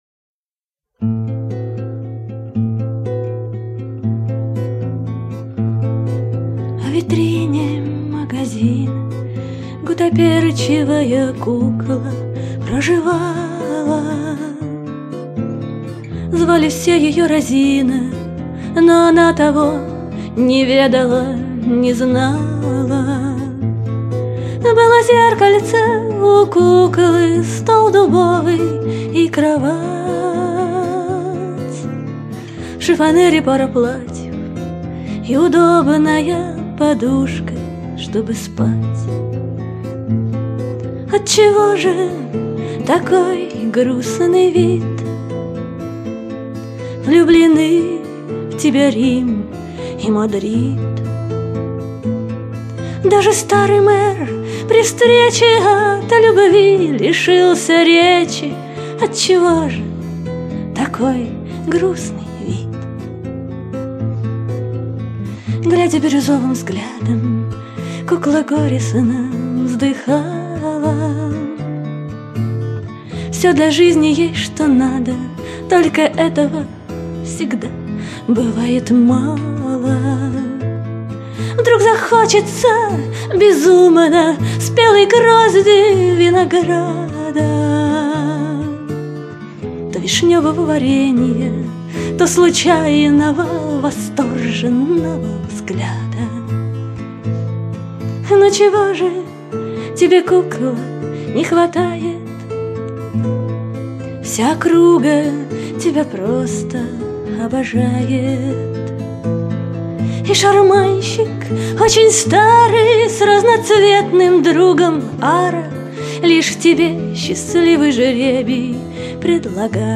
Гитары